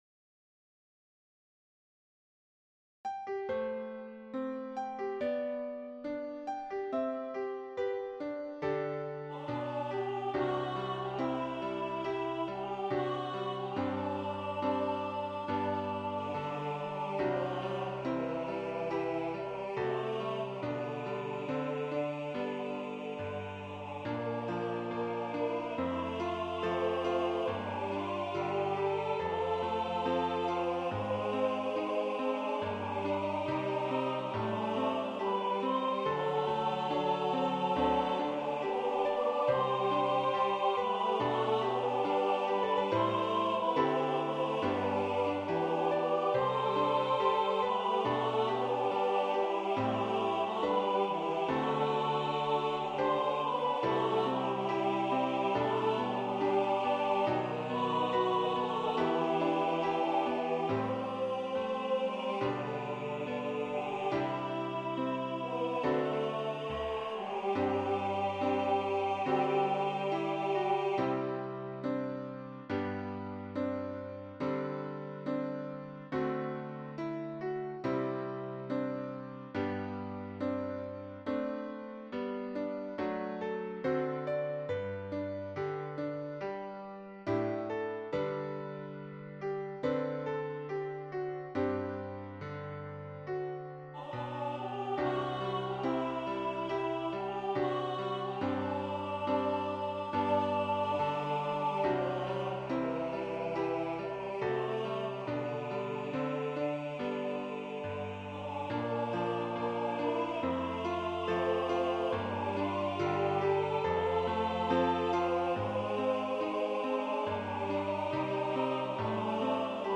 Voicing/Instrumentation: SAB